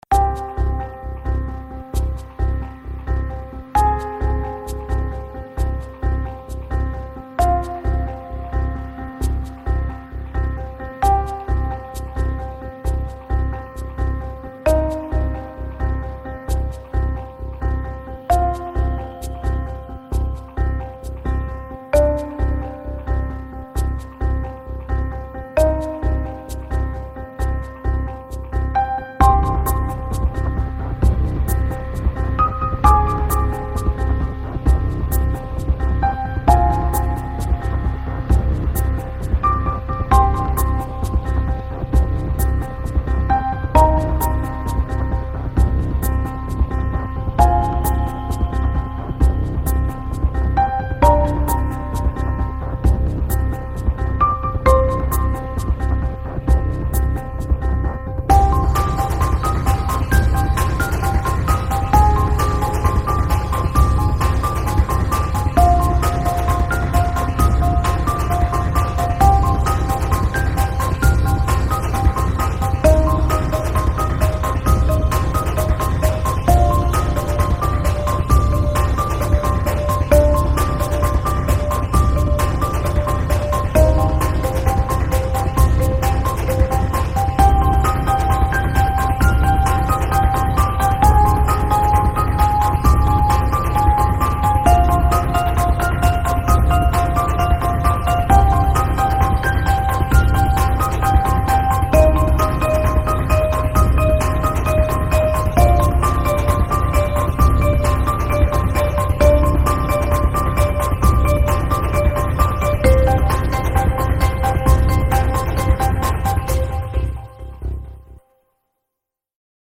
Música-Cinematográfica-de-Intriga-Y-Suspenso.mp3